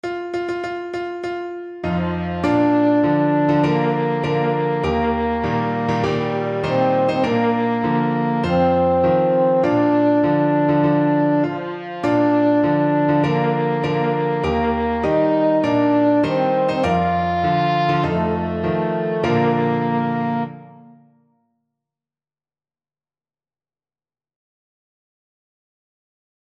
French Horn
4/4 (View more 4/4 Music)
Bb major (Sounding Pitch) F major (French Horn in F) (View more Bb major Music for French Horn )
With gusto!
Traditional (View more Traditional French Horn Music)